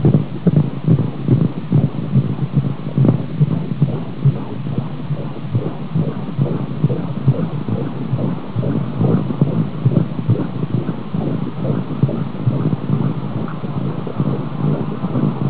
Babyens hjerterytme kan nu høres via en doopler skærm
Du vil bemærke, at hjerteslagene er helt op til 160 slag i minuttet, dobbelt så hurtig som hos et normalt voksent menneske.
heartbeat.au